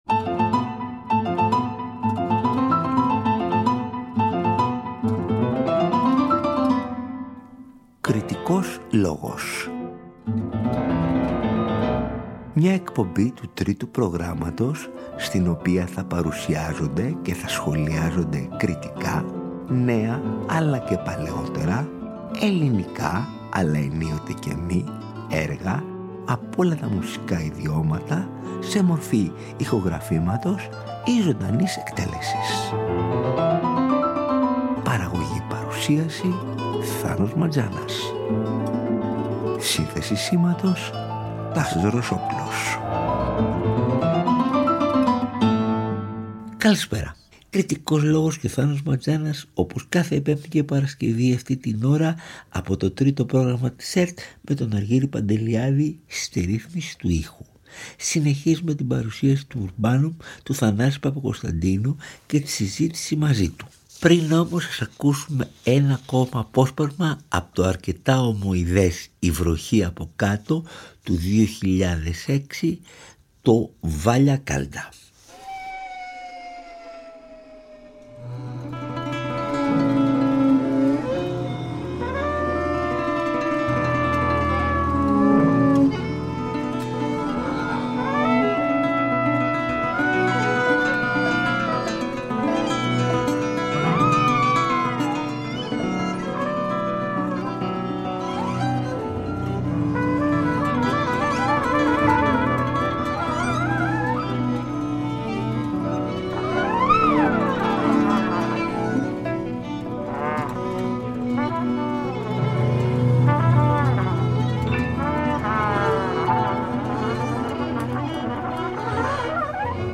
παρουσιάζει και αναλύει μαζί με τον δημιουργό